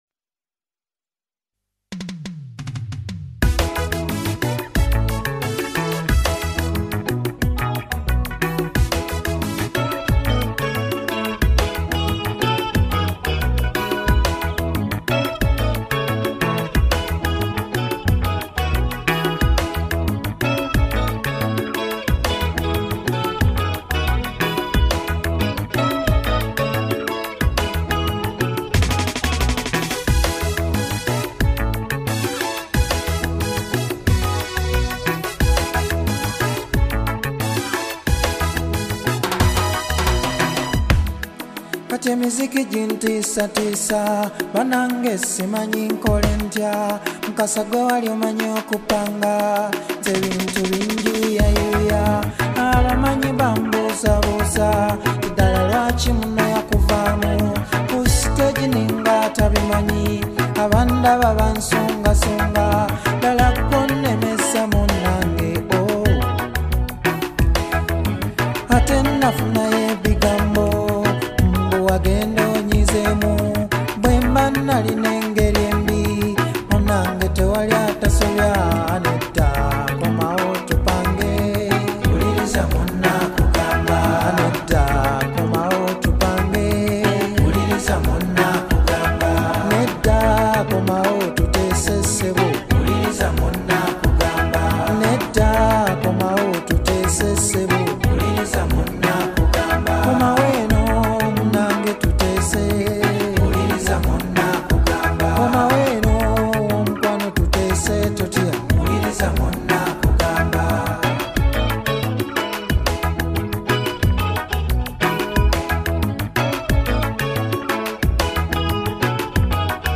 Genre: Kadongo Kamu